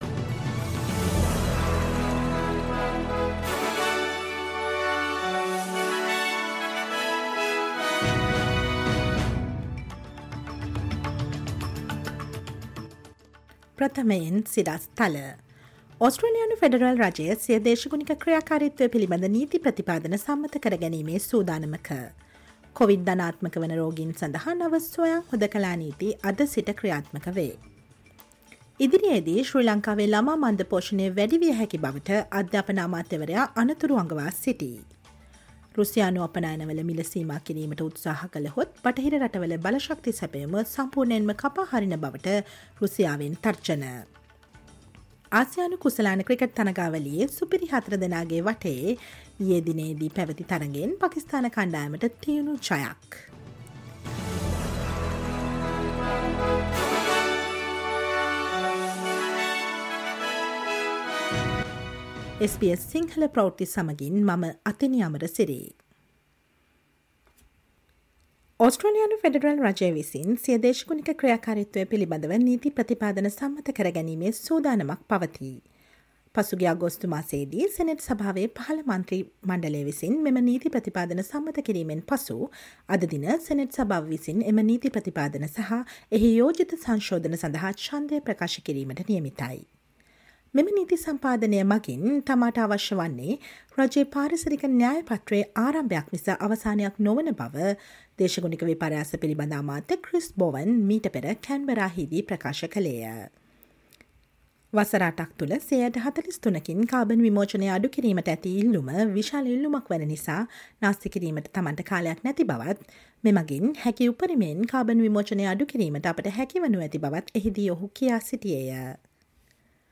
Listen to the latest news from Australia, Sri Lanka, and across the globe, and the latest news from the sports world on SBS Sinhala radio news on Thursday, 08 September 2022